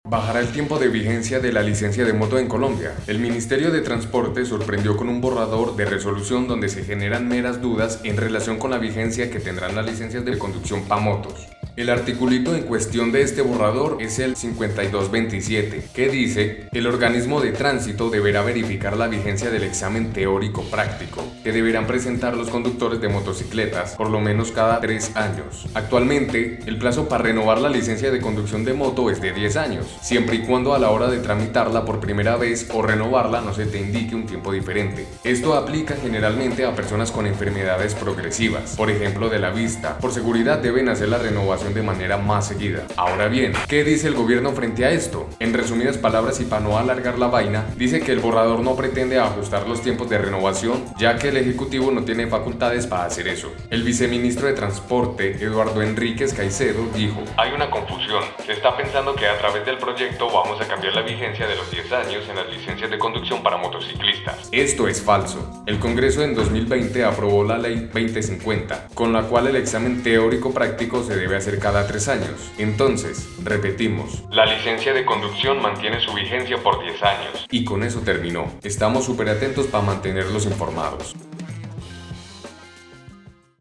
No te preocupés, acá te lo leemos.